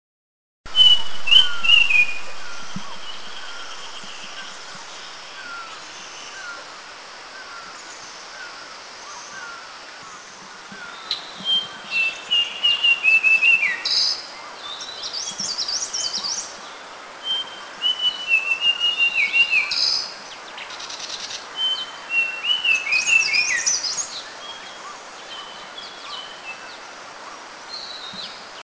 Pale Blue-Flycatcher
song
Cyornis unicolor
PaleBlueFlycatcher.mp3